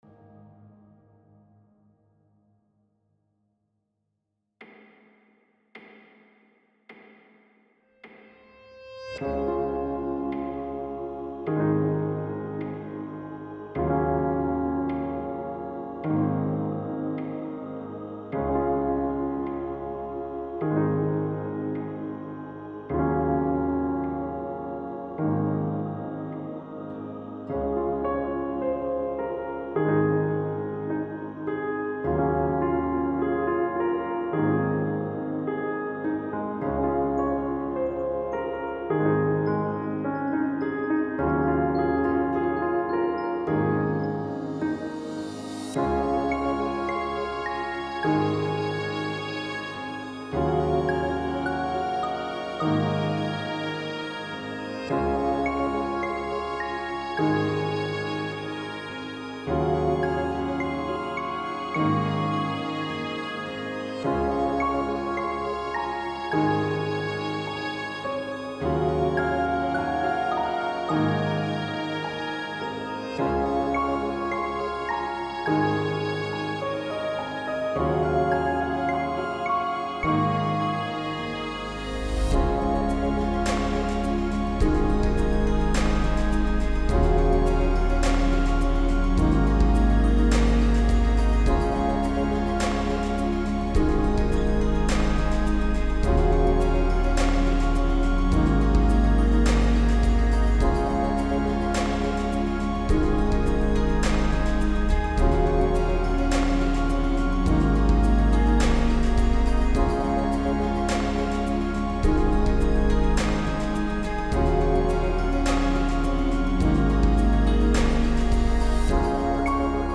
Genre: Emotional